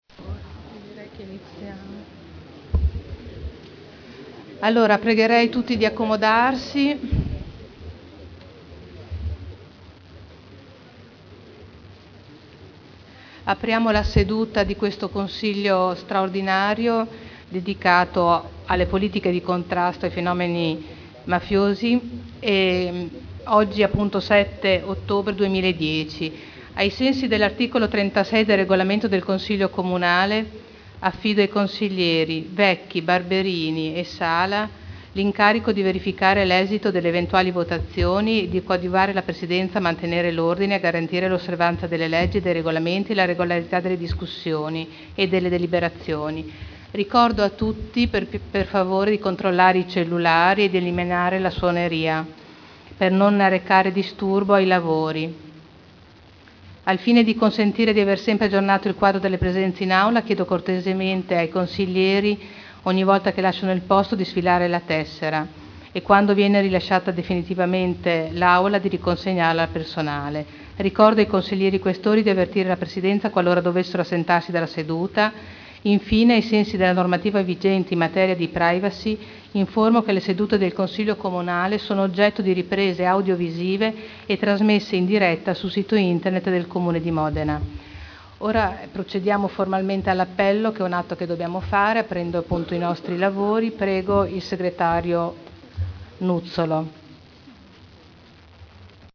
Seduta del 07/10/2010. Il Presidente Caterina Liotti apre i lavori del Consiglio Comunale del 7 ottobre 2010.